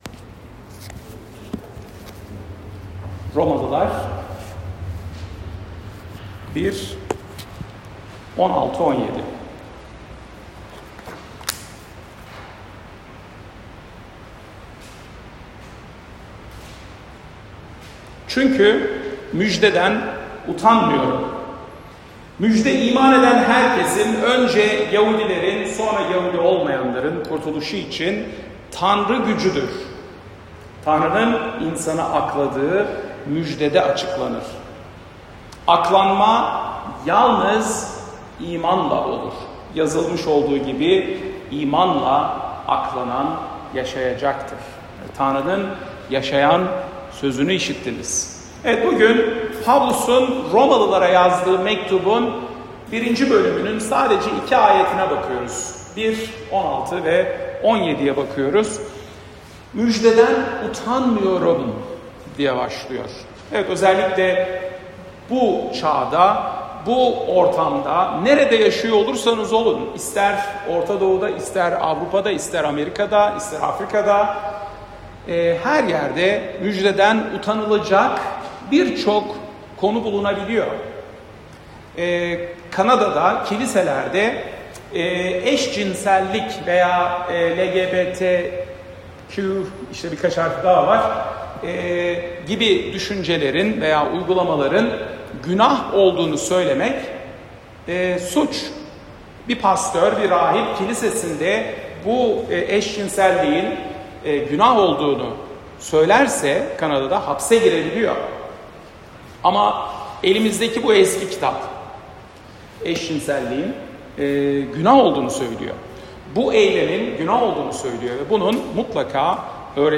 Salı, 1 Nisan 2025 | Romalılar Vaaz Serisi 2024-26, Vaazlar